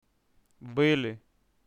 B'ili